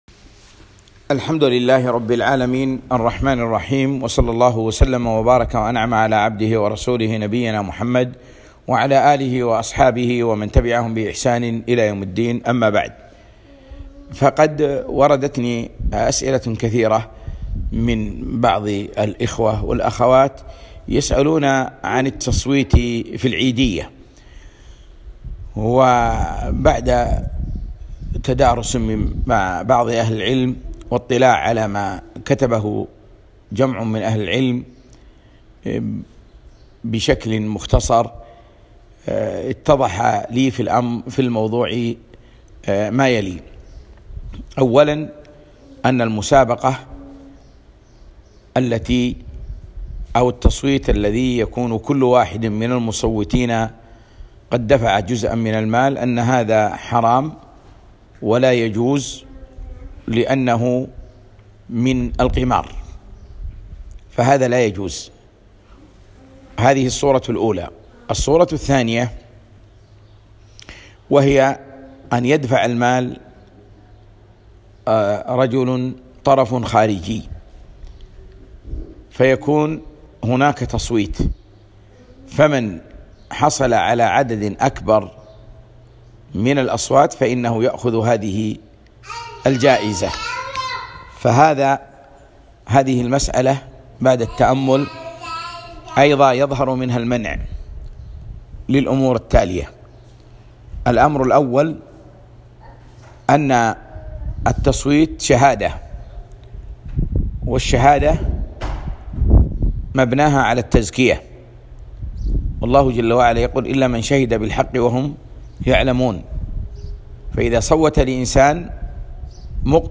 كلمة حول التصويت العائلي في العيدية